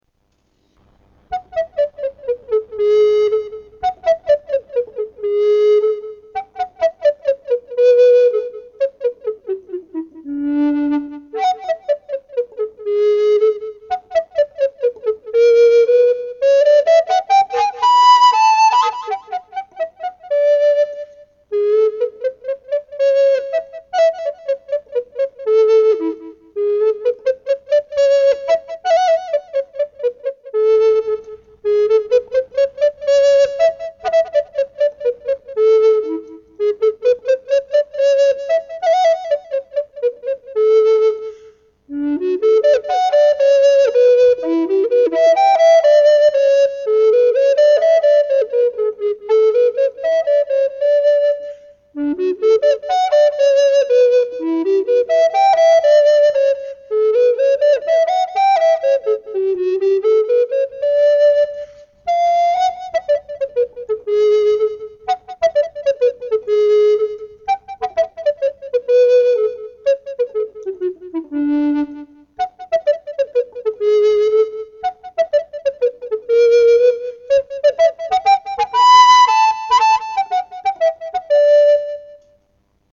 Roland's Five-Step Waltz (Edward de Roland), tenor recorder